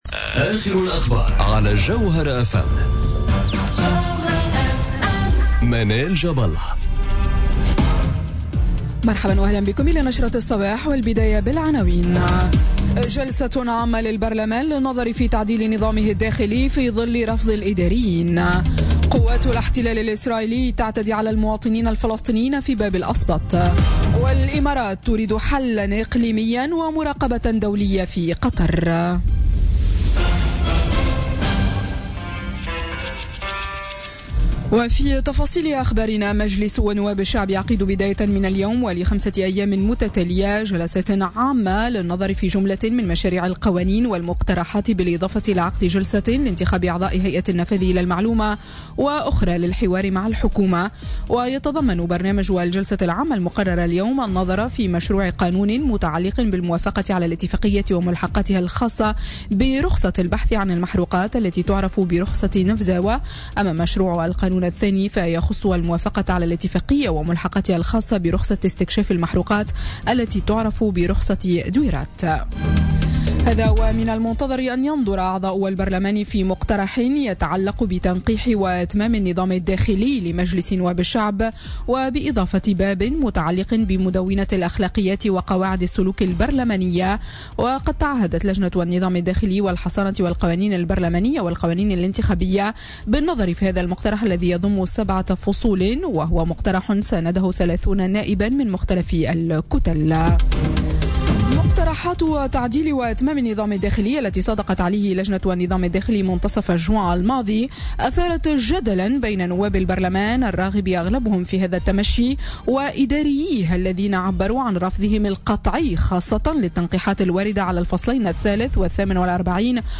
نشرة أخبار السابعة صباحا ليوم الإثنين 17 جويلية 2017